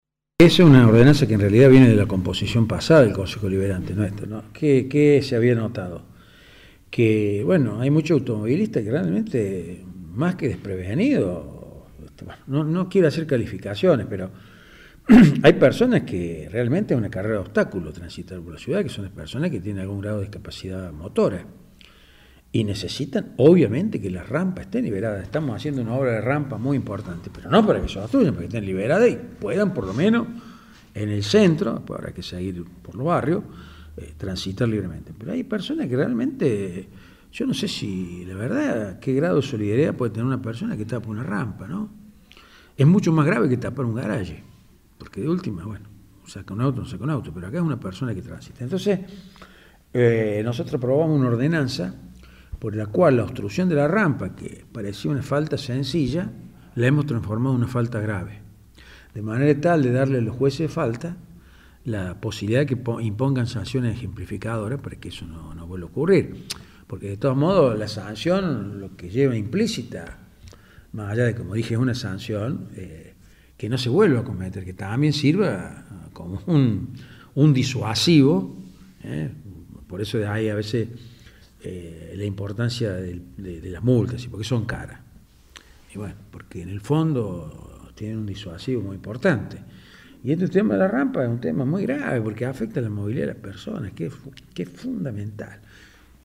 El presidente del Concejo Deliberante, Carlos De Falco explicó a Canal 20 sobre este tema.